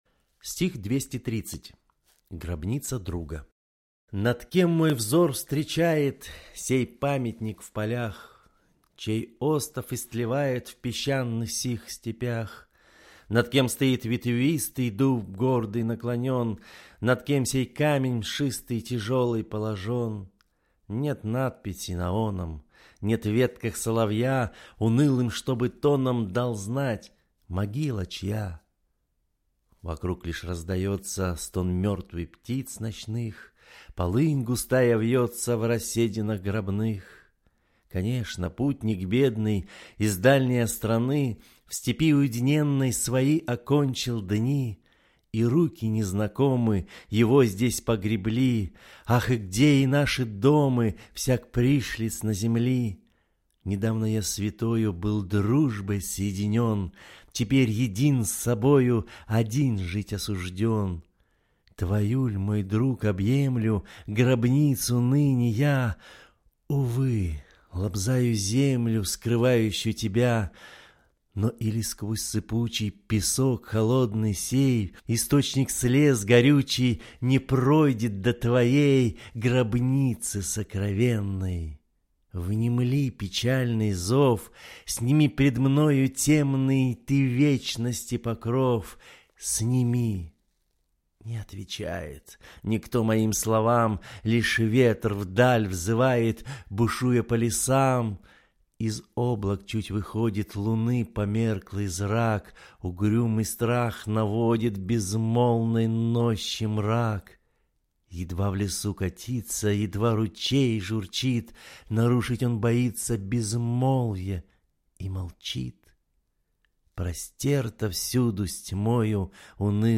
Аудиокнига Стихотворения (1810 г.)